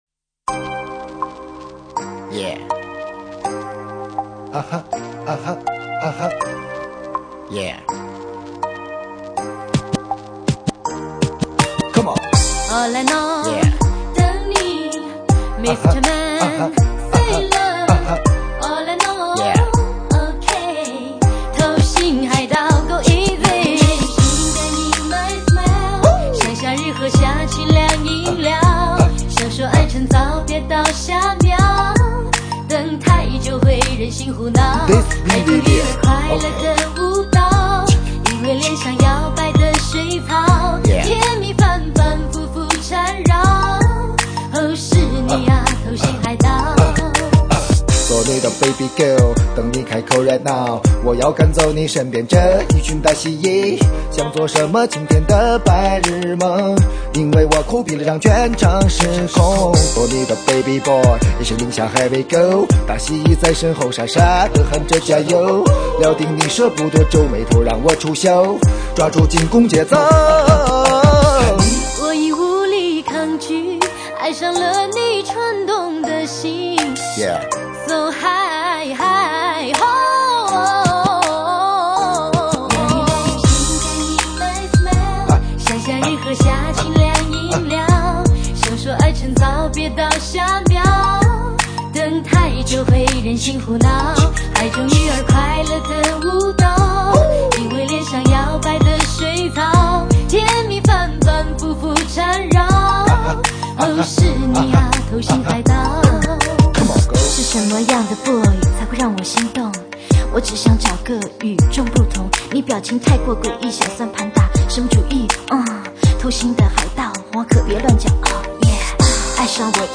风格流派： POP
轻叩心灵、摇摆风趣的热门主打